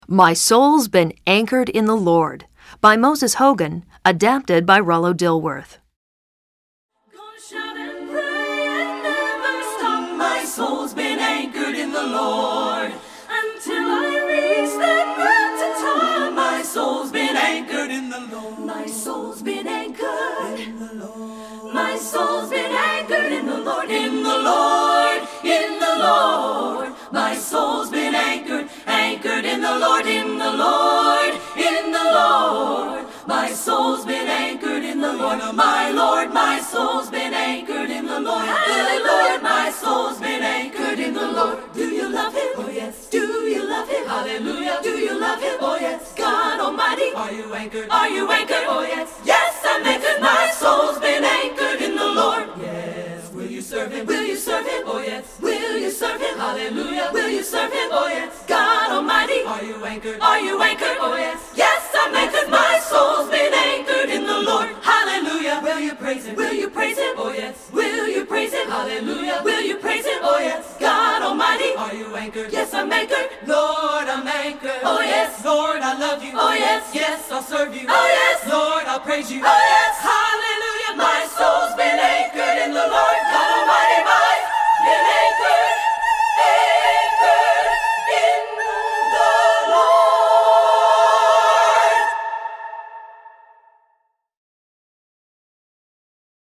Composer: Spiritual
Voicing: SSAA